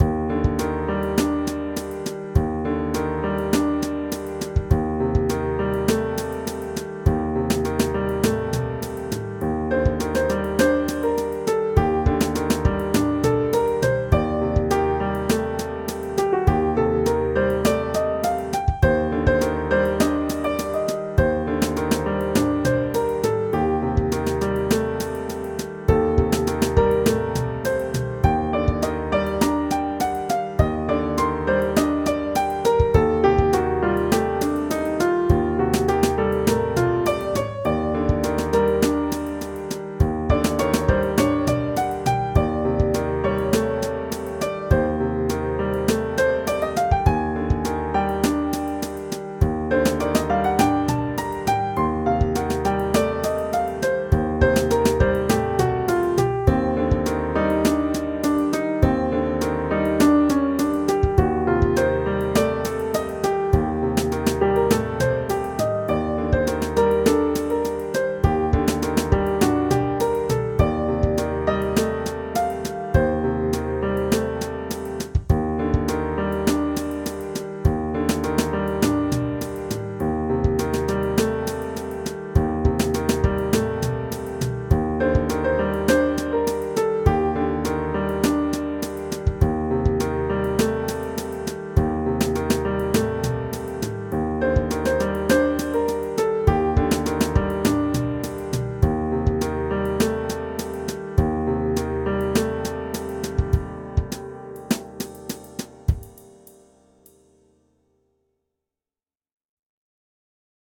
Pieza de jazz
Música electrónica
percusión
piano
melodía
rítmico
sintetizador